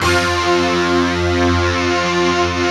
Index of /90_sSampleCDs/Optical Media International - Sonic Images Library/SI1_DistortGuitr/SI1_DistGuitrMut